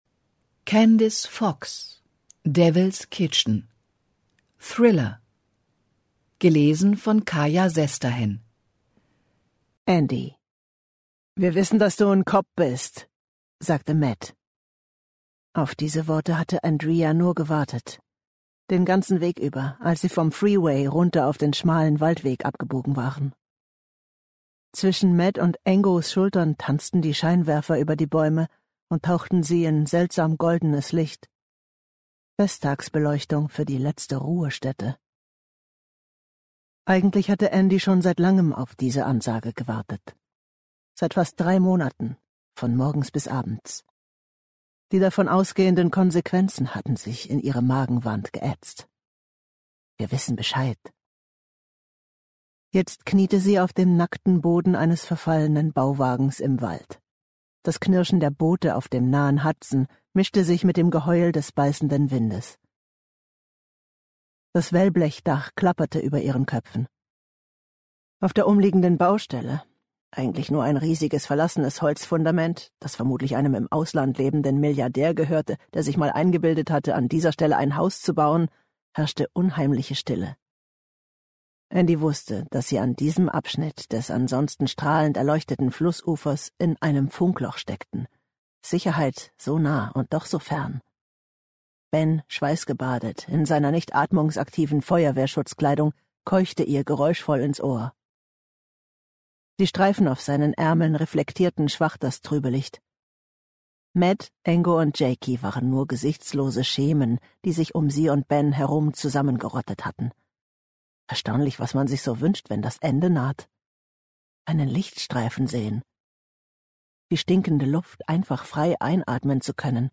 Diesen spannenden Roman der bekannten australischen Autorin liest